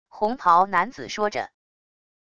红袍男子说着wav音频